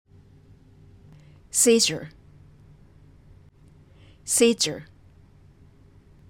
seizure は /sı́ːʒər/ であり、/sı́ːər/ ではありません。
◆【聴き比べてみましょう】 /sı́ːʒər/ vs /sı́ːdʒər/、違いがわかるでしょうか？